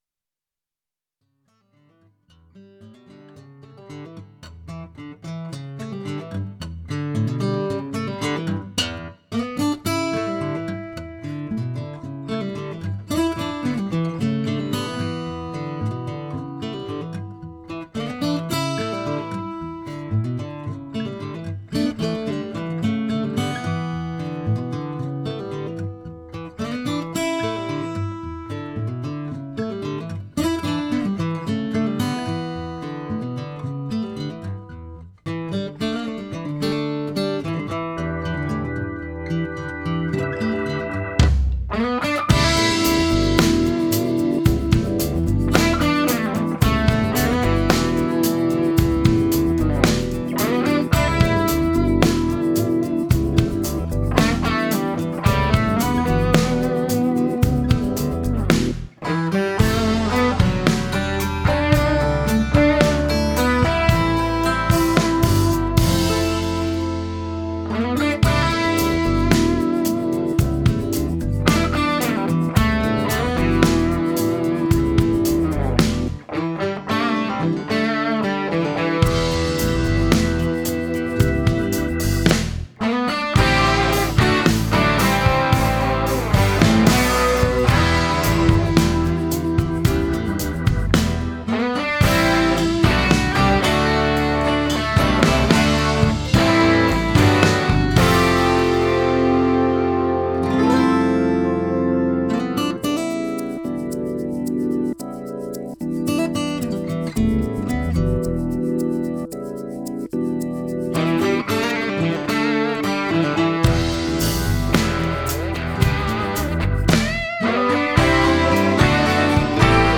MP3 Audio Clip  Fusion / Acoustic Lindsey Buckingham / Robben Ford Les Paul, 335, 5E3-P2P, Straight In None
Signal chain: DIY Teletronix La2a and Pultec EQP-1a, Revolution Redd.47 Preamp, Purple Audio MC76, Altec 438a. Microphones: Vintage Shure Unidyne III, Sennheiser e906 (close) and AKG 414 (room). Speakers: Speed Shop A12Q, Celestion Blue, G12M Heritage. Amps: Various RecProAudio Tweed Deluxe P2P and Studio-Series with NOS Tubes: RCA 6V6GT, RCA 12AX7, RCA 12AY7, RCA 5Y3.